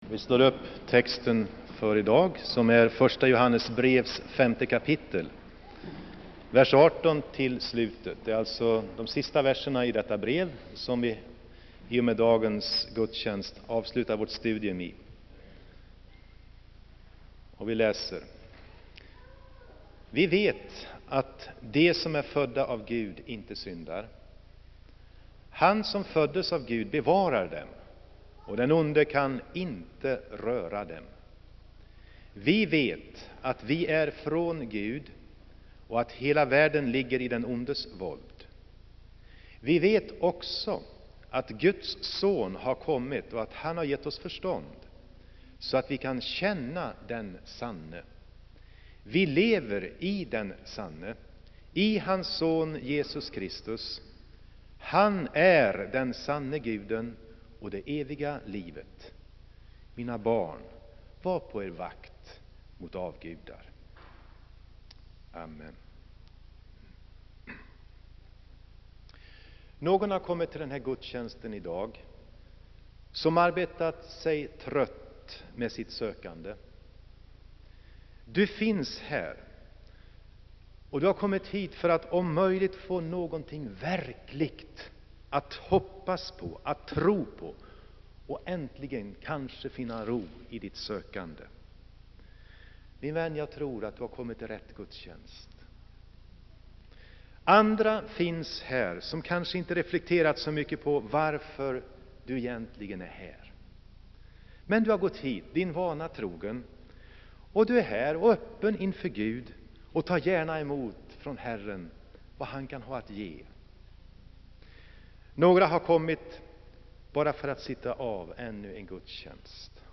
Inspelad i Saronkyrkan, Göteborg.